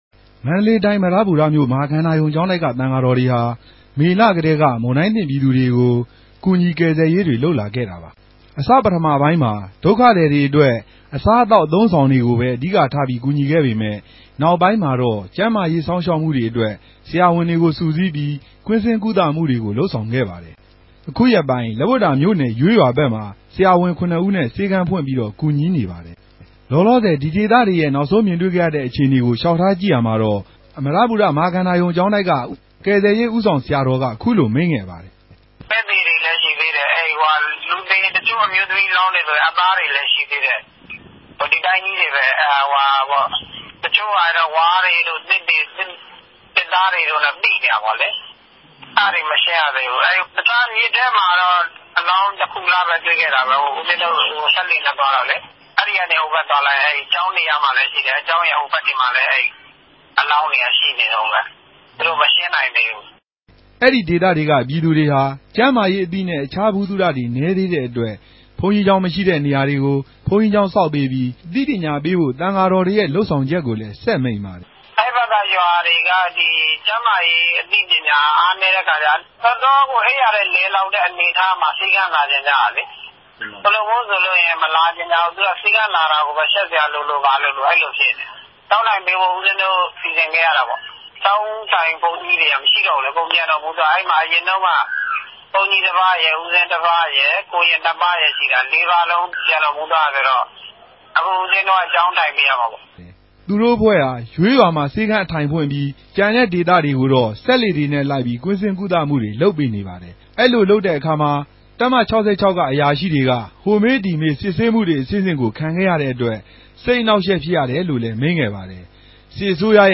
ပၝဝင်ဆောင်ရြက်နေတဲ့ သံဃာတော်တပၝးကို ဆက်သြယ်္ဘပီး တင်ူပထားတာကို နားဆငိံိုင်ပၝတယ်။